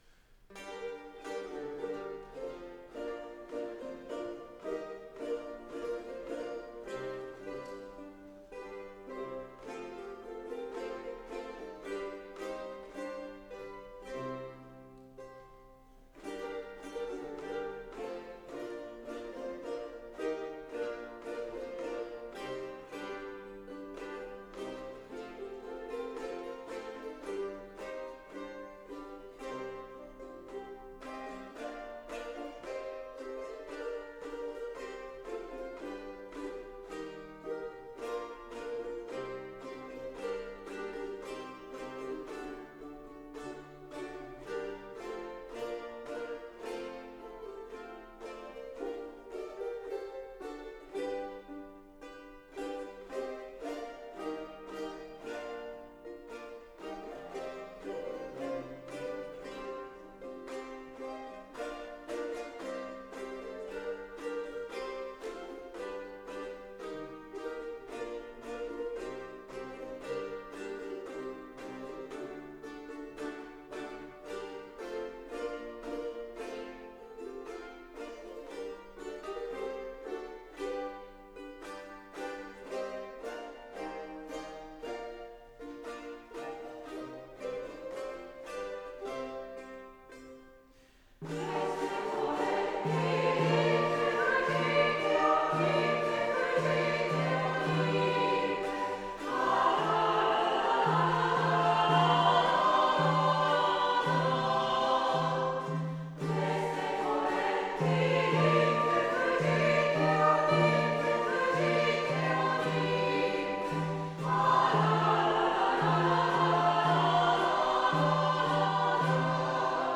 プログラムの最初の二曲は全体演奏で、私もルネサンスギターで参加したのですが、その時の録音をどうぞ。
ルネサンスギター４本、ウクレレ5、6本、歌は大体10人ほどという構成。ウクレレ経験は２、3年以内の初心者が大多数。